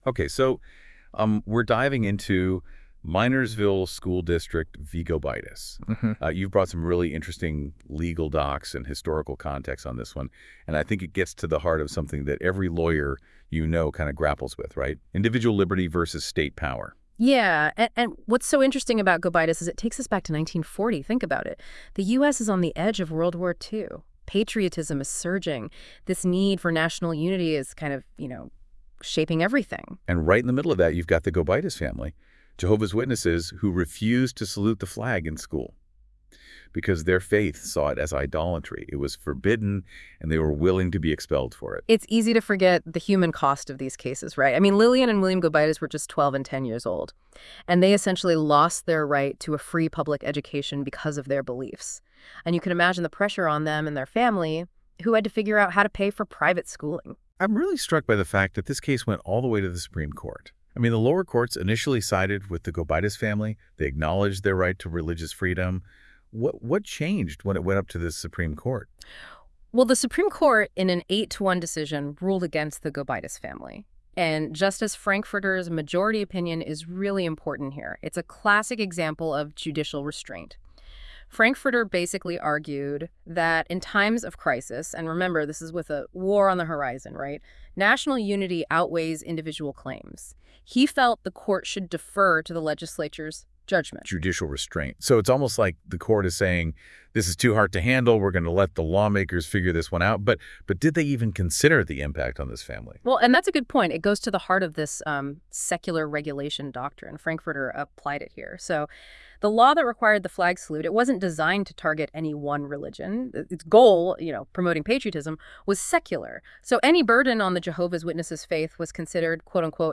Listen to an audio breakdown of Minersville School District v. Gobitis.